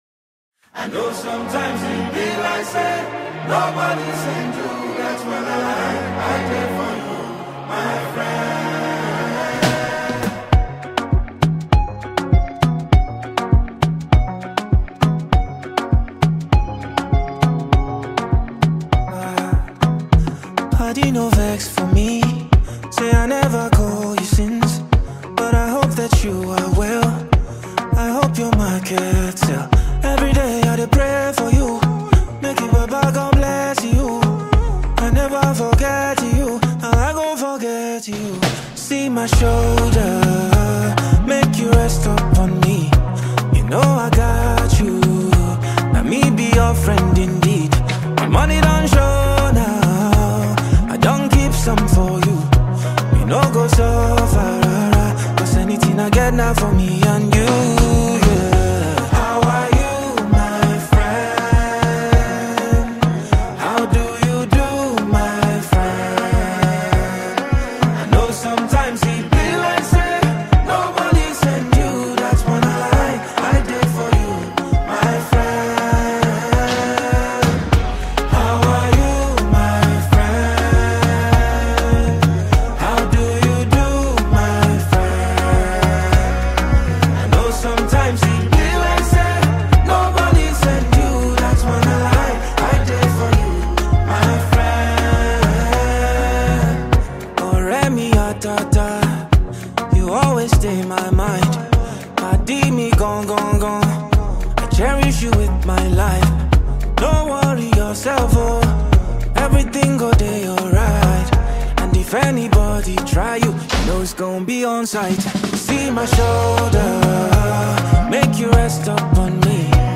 Pop-genre record